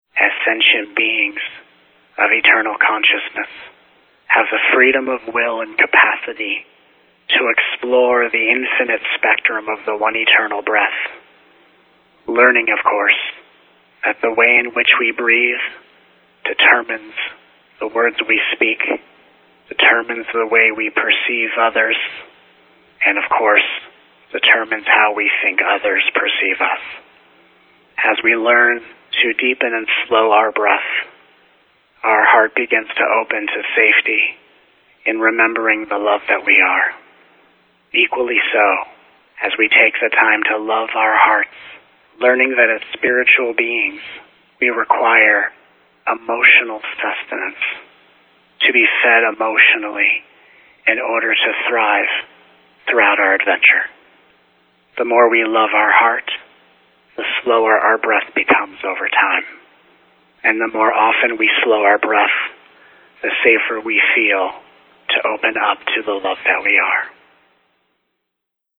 This Angel Academy 6 telegathering course is an opportunity to: